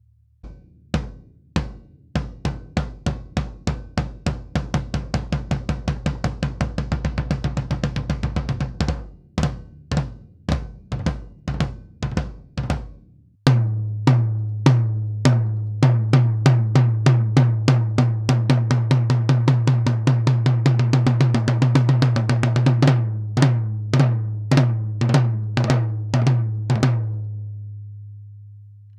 Schlagfell: Amba coated, Reso CS Black dot. Abstand zur Trommel: rund 10 cm
Sennheiser e 604
Die Trommeln klingen auch in Natur recht dumpf - wie früher eben.